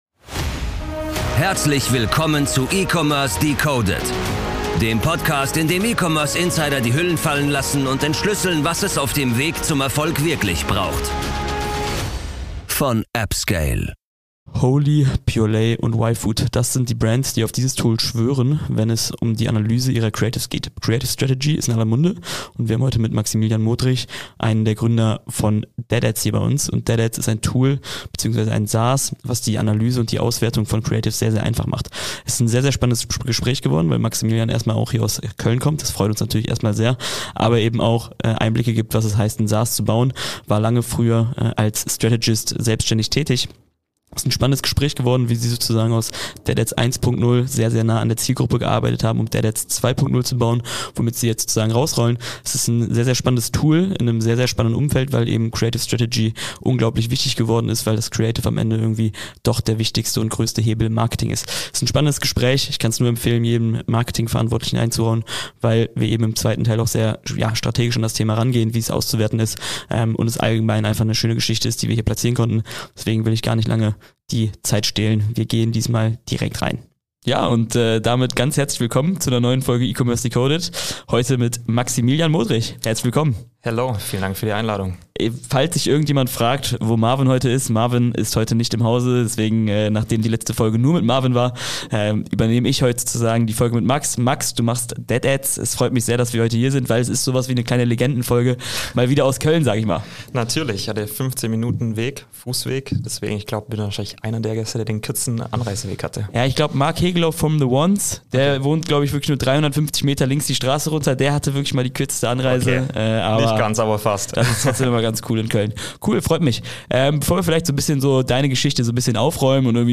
Es ist ein sehr spannendes Gespräch über Creatives und Zahlen geworden.